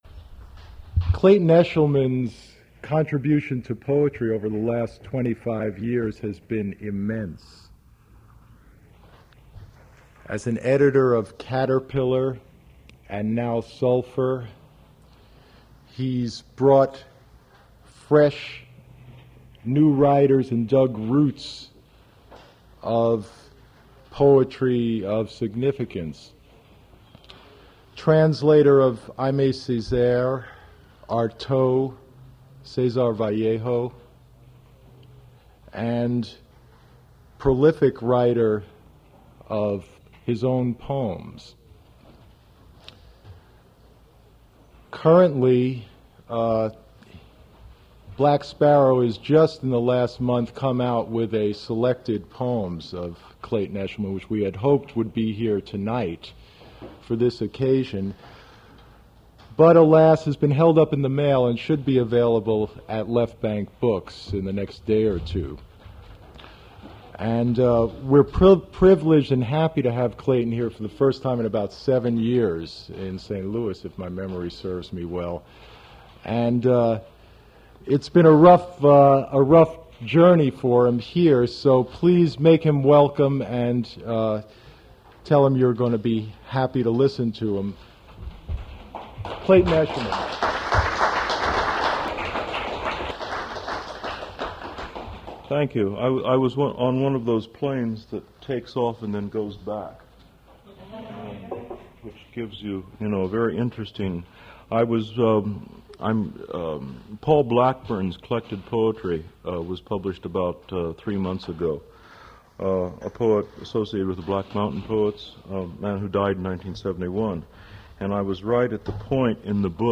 Poetry reading featuring Clayton Eshleman
Attributes Attribute Name Values Description Clayton Eshleman poetry reaing at Duff's Restaurant.
mp3 edited access file was created from unedited access file which was sourced from preservation WAV file that was generated from original audio cassette.
Audio breaks at 38:29